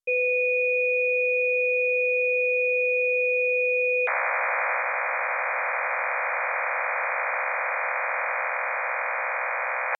Beschreibung 64-Ton Phasenmodulation für störungsfreie
Ein MT63-Signal hört sich wie erhöhtes Rauschen an und ist bei sehr geringen Feldstärken auf Kurzwelle äußerst schwierig zu finden.
MT63-2000L